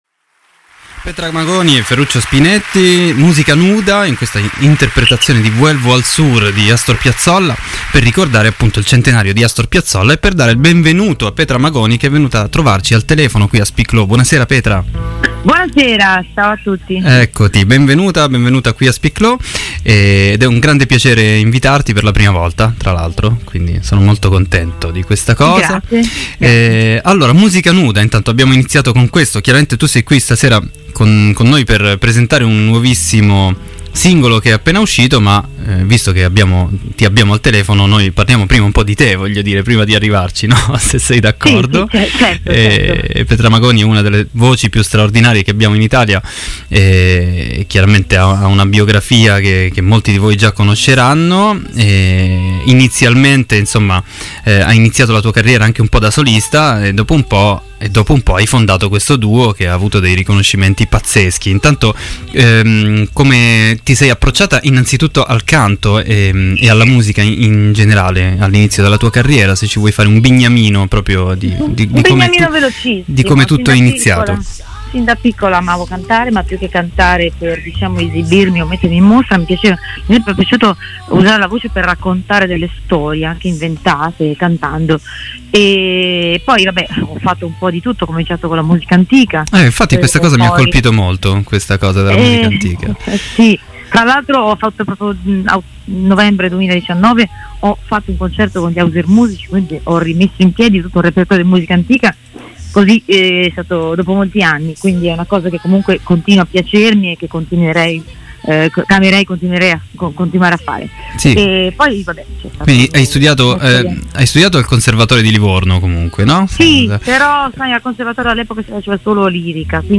Intervista a Petra Magoni. Speak Low 12.03.21 | Radio Città Aperta
Interviste